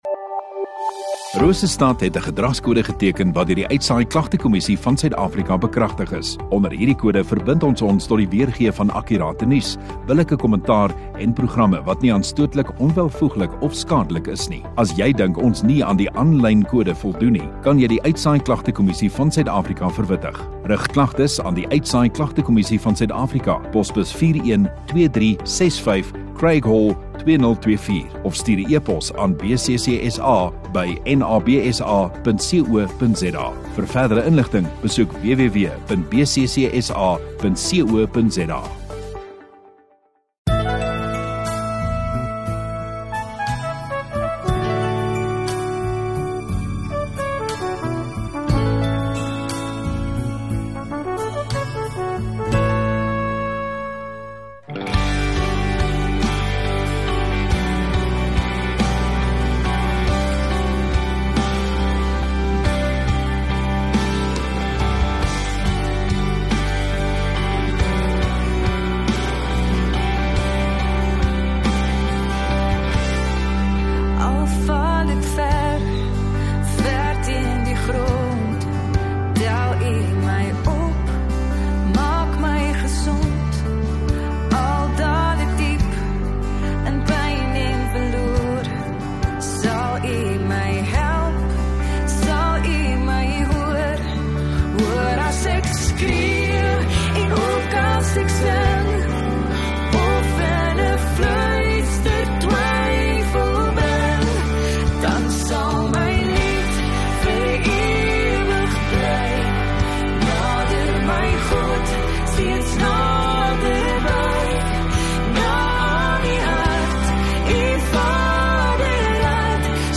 4 Oct Vrydag Oggenddiens